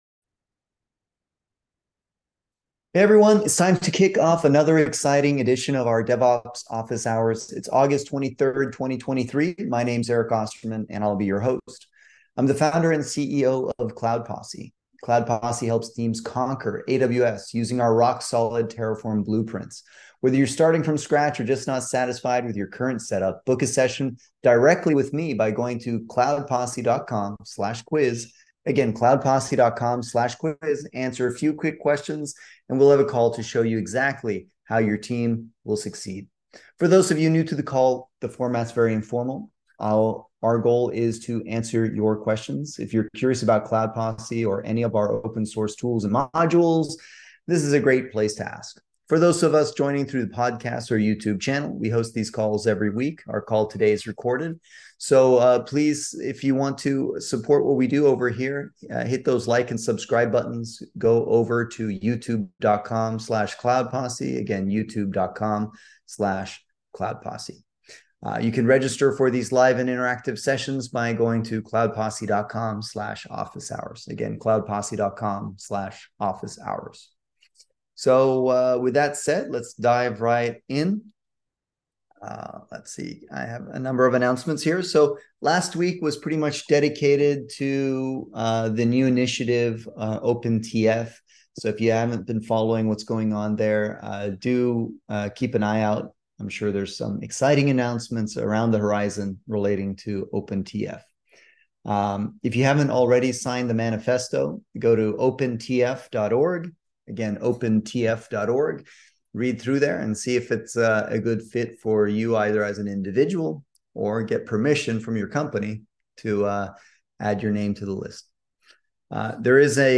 Cloud Posse holds public "Office Hours" every Wednesday at 11:30am PST to answer questions on all things related to DevOps, Terraform, Kubernetes, CICD. Basically, it's like an interactive "Lunch & Learn" session where we get together for about an hour and talk shop.